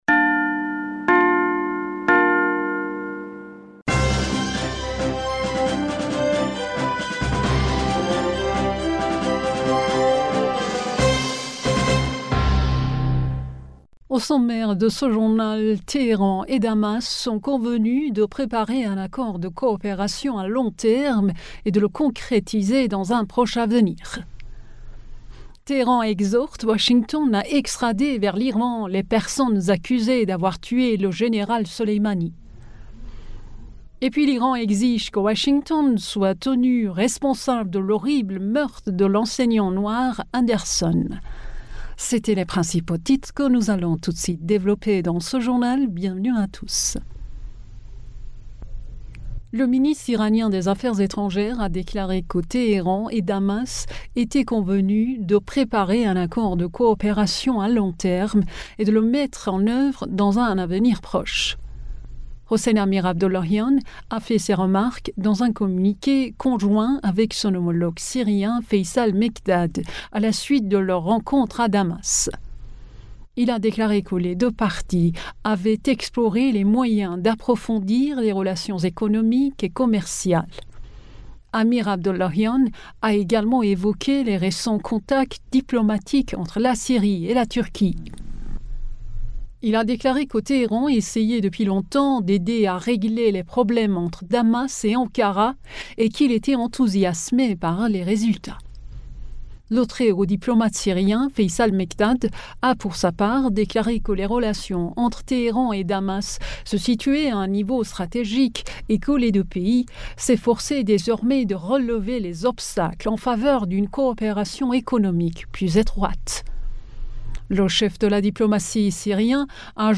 Bulletin d'information du 15 Janvier